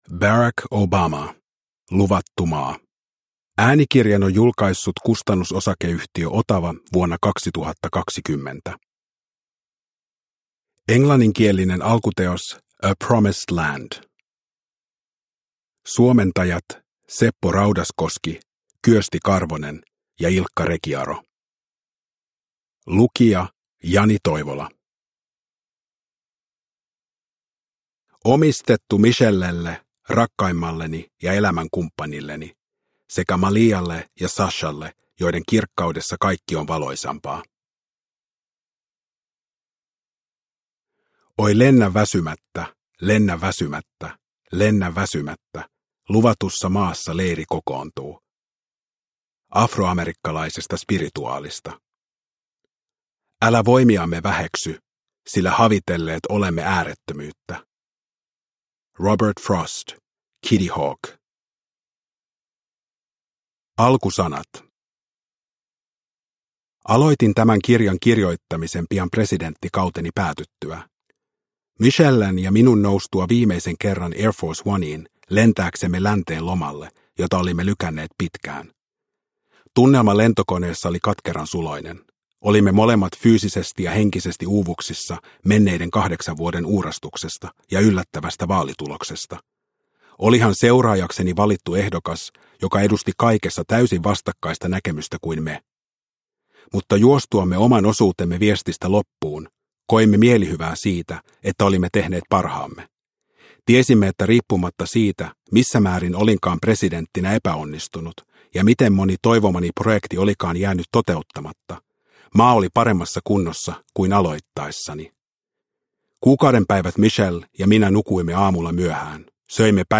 Luvattu maa – Ljudbok – Laddas ner